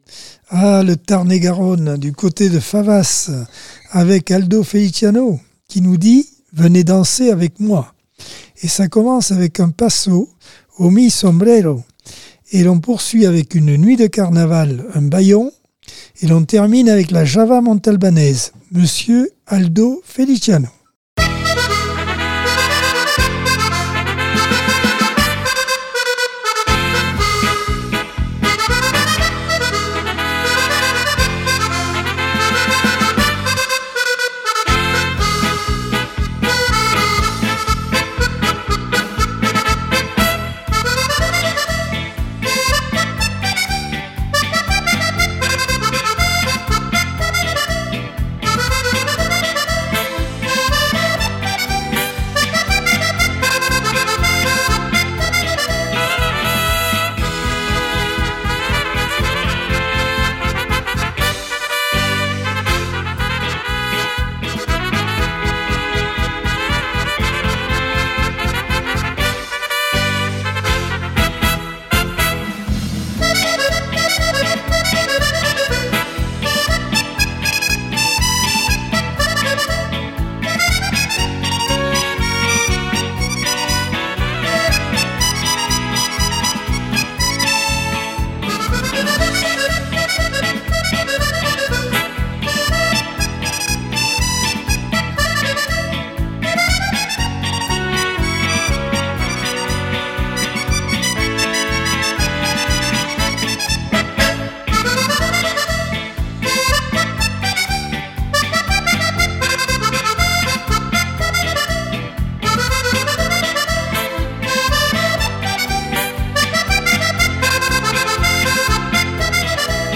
Accordeon 2024 sem 19 bloc 4 - Radio ACX